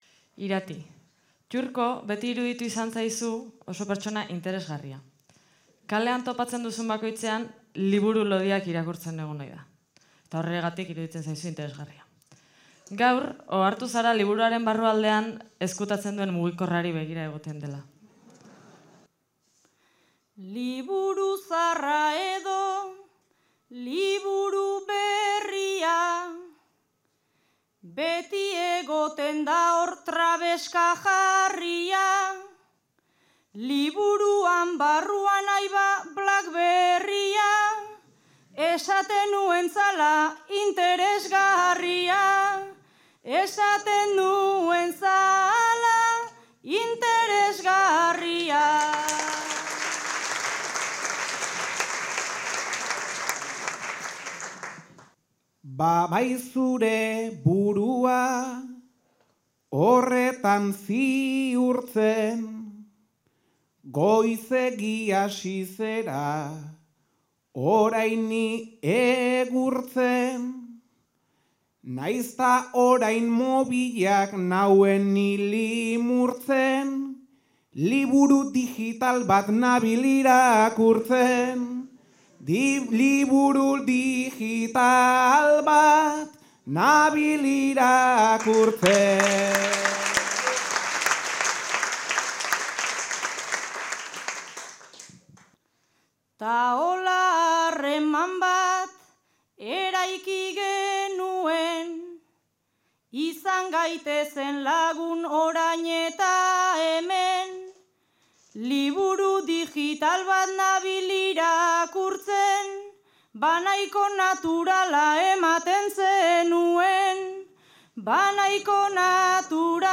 Zarautz (Gipuzkoa)
Zortziko txikia.